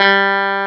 CLAV2HRDG3.wav